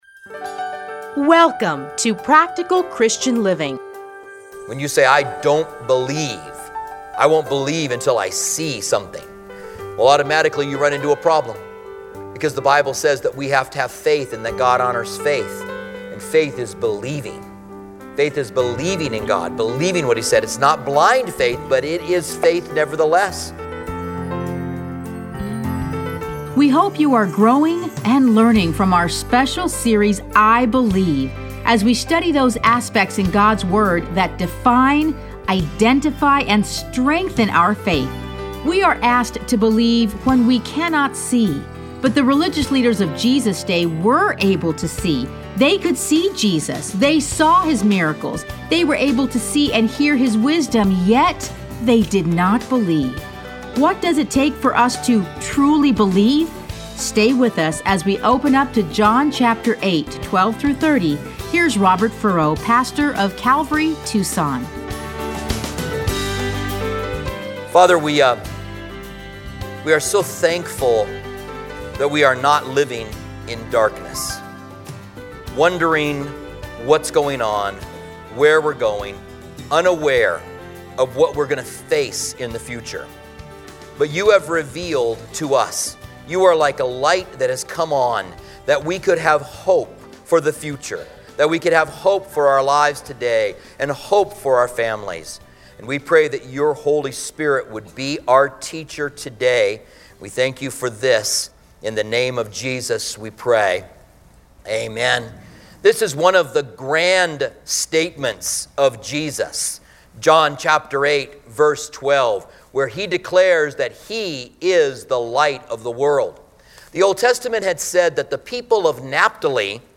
Listen to a teaching from John 8:12-30.